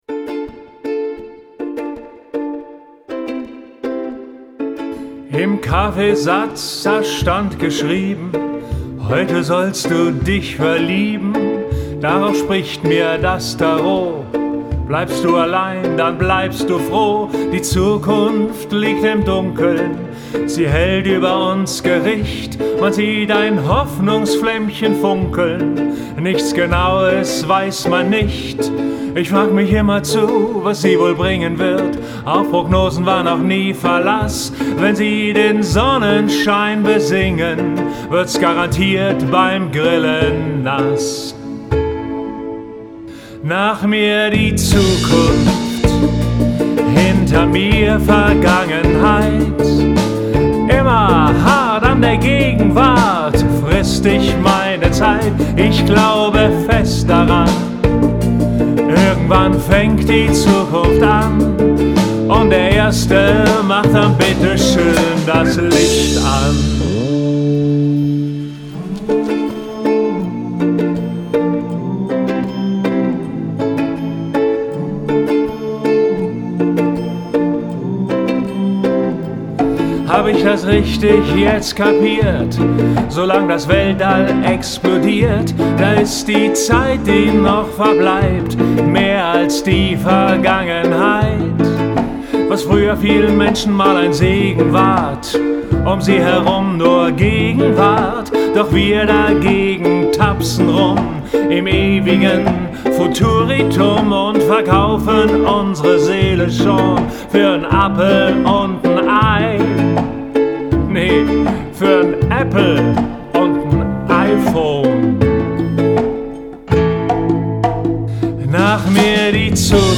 Musikkabarettistische Hörbeispiele:
Witziger Future-Song